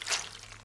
gib_step.ogg